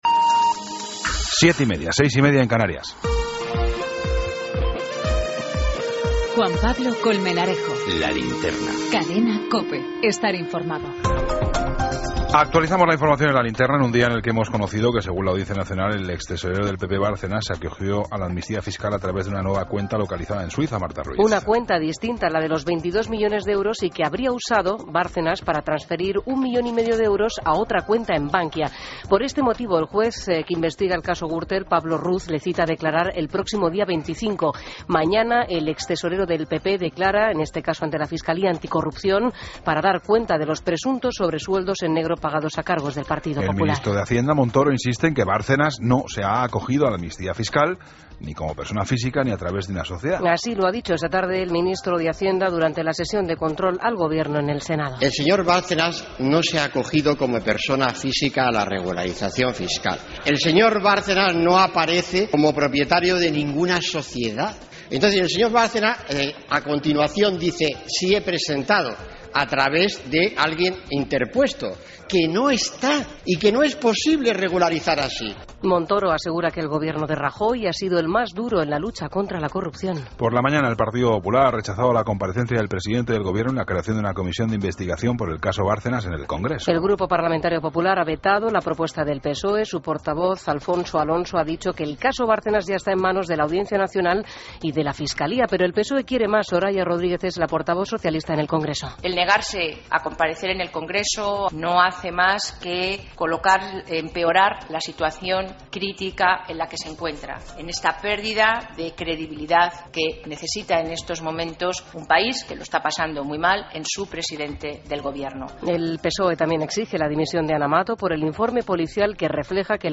Ronda de corresponsales.
Entrevistas